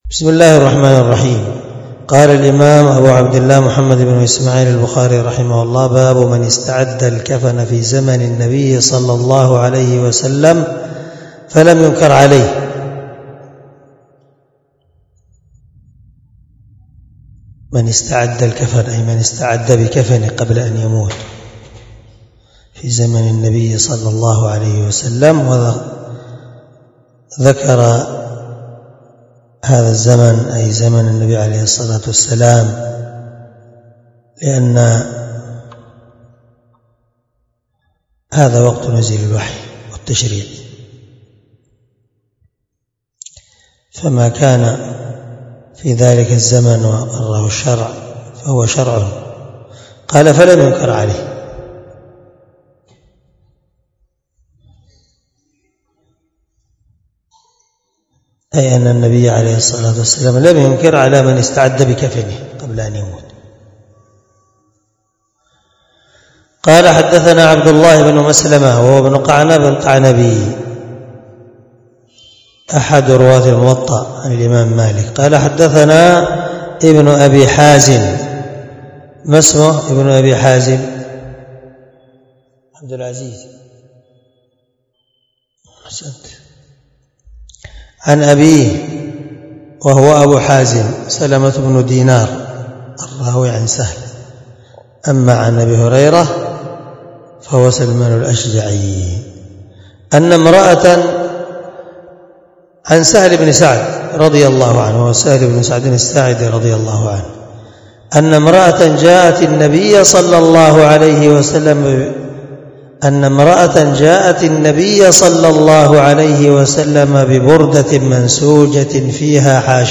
743الدرس 16من شرح كتاب الجنائز حديث رقم(1277 )من صحيح البخاري
دار الحديث- المَحاوِلة- الصبيحة.